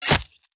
combat / weapons
flesh2.wav